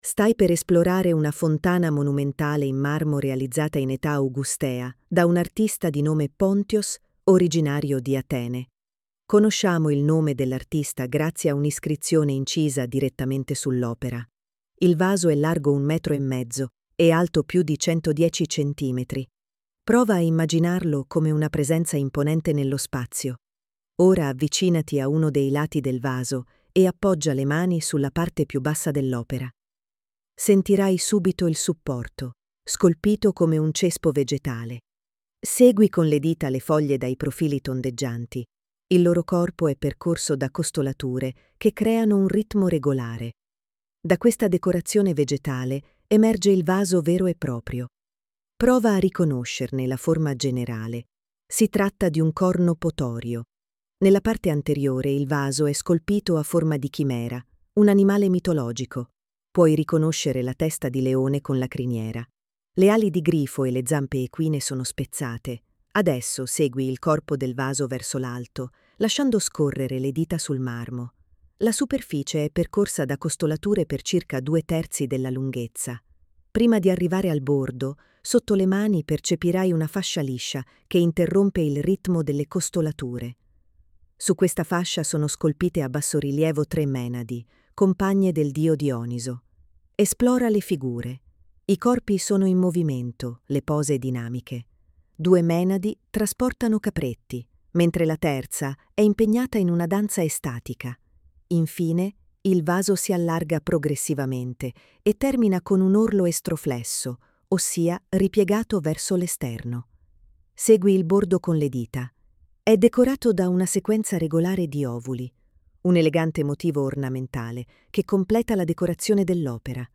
•    9 AUDIODESCRIZIONI che accompagnano il visitatore nell’esplorazione delle opere, ognuna indicata da didascalia in Braille e guida audio, con il relativo testo, fruibile tramite QR code: